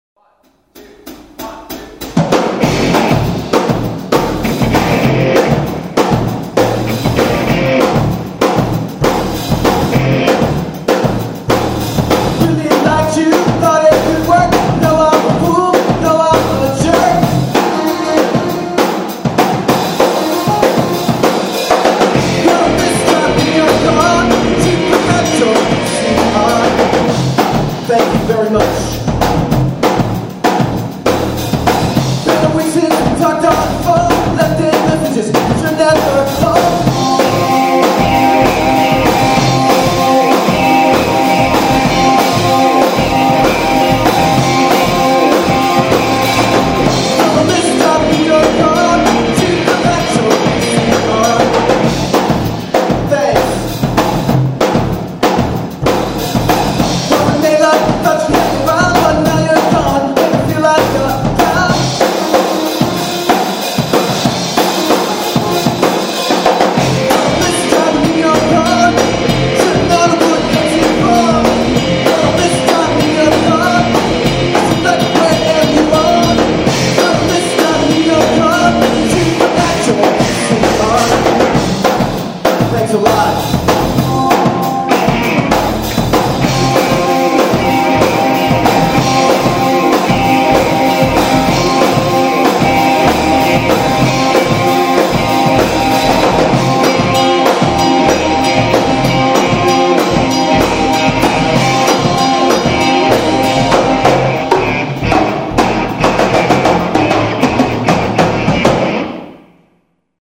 Polyrhythmic, eclectic, very modern 'art funk-rock' music.